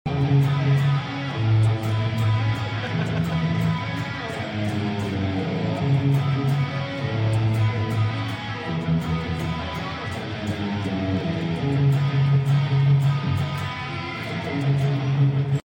In case you were curious of its first sounds on stage